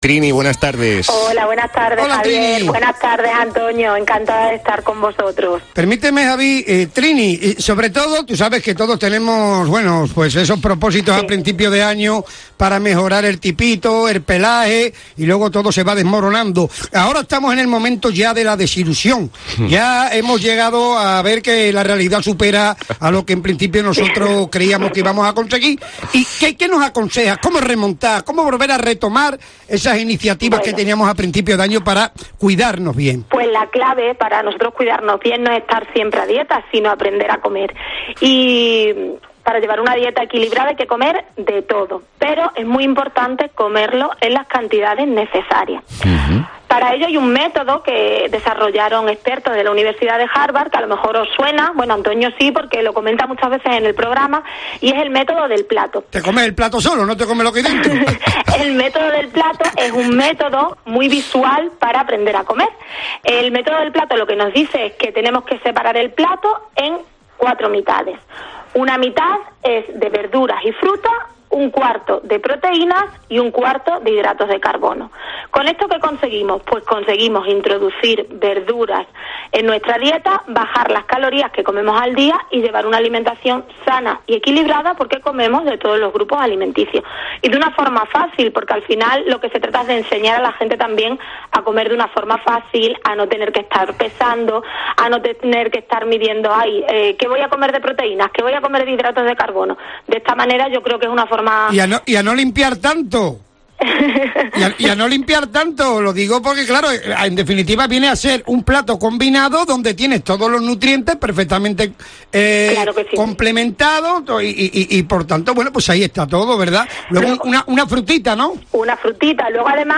Se trata de un método para poder planificar y diseñar platos que contengan los grupos de alimentos que hay que incluir siempre en nuestras comidas principales: verduras, hidratos de carbono y alimentos proteicos. Si tienes dudas sobre qué incluye cada uno de los grupos puedes volver a escuchar la entrevista.